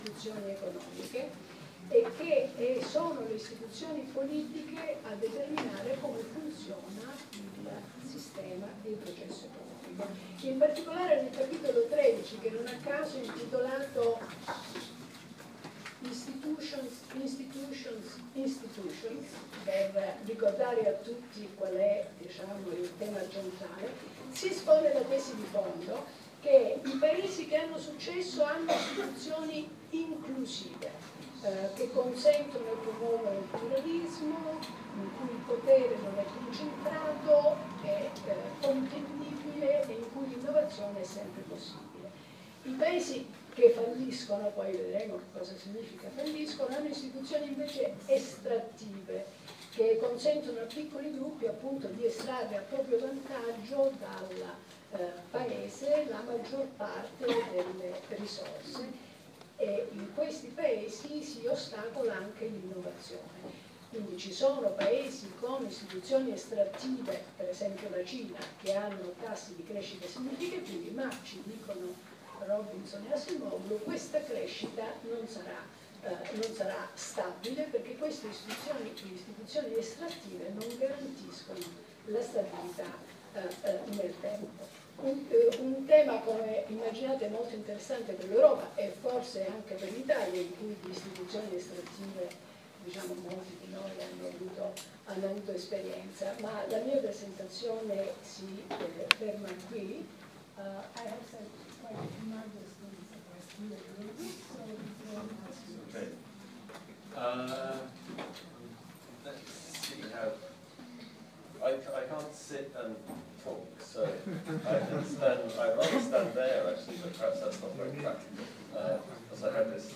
Si pubblica la registrazione della settima Irpa Lecture, tenuta dal Prof. Robinson della Harvard University dal titolo “Why Nations Fail?”